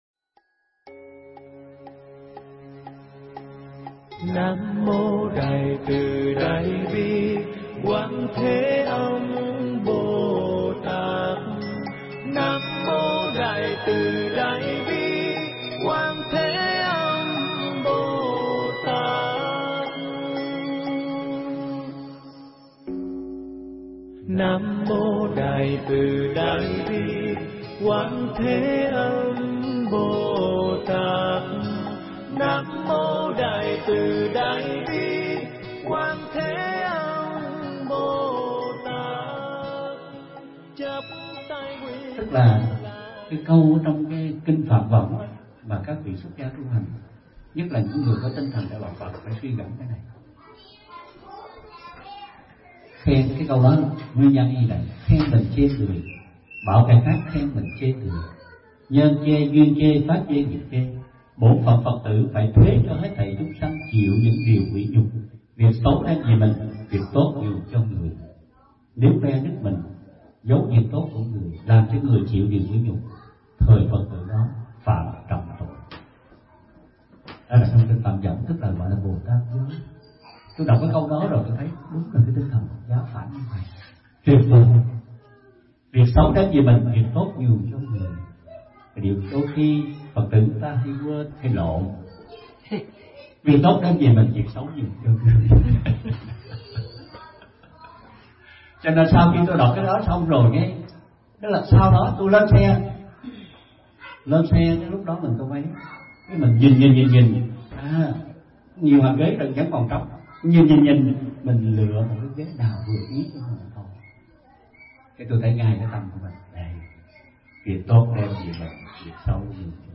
thuyết pháp
tại tinh xá Hương Thiền, Virginina, USA